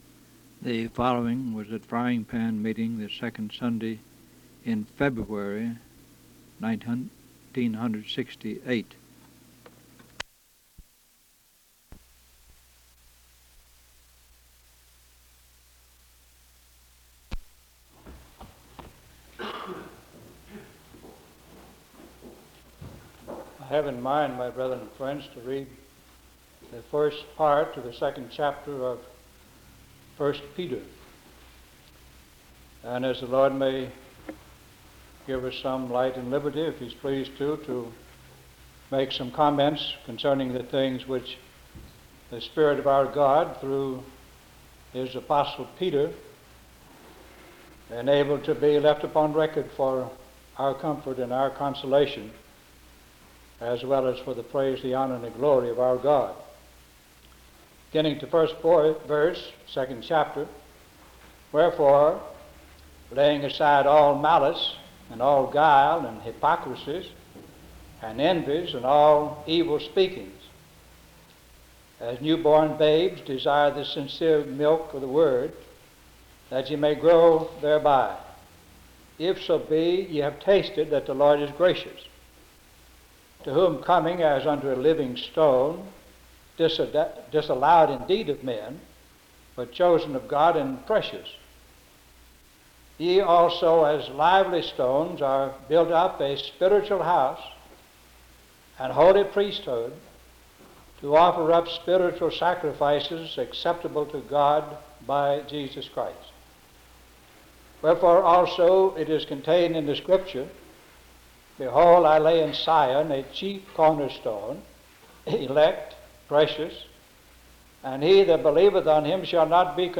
Herndon (Va.)